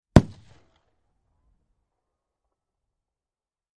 HK-21 Machine Gun Single Shot From Medium Point of View, X4